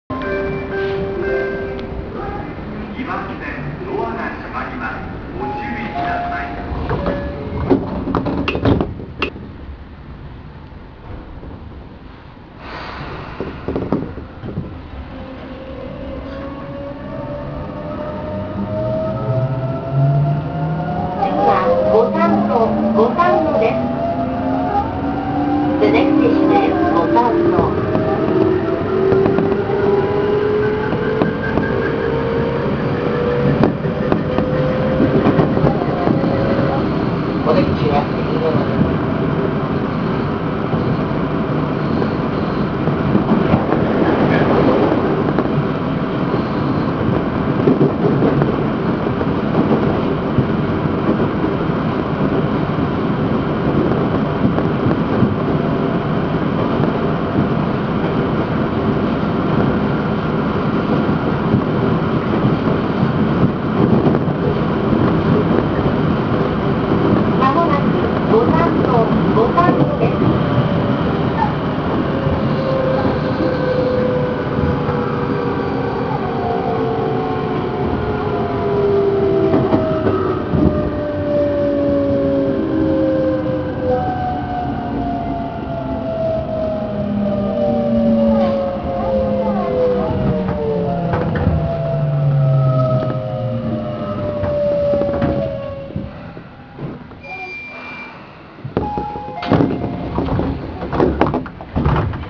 ・20070系走行音
【伊勢崎線】小菅〜五反野（1分42秒：556KB）
基本的に20050系や9050系等となんら変わらない音です。